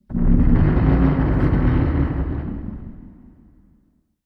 Fantasy Creatures Demo
Abyss_monster_1.wav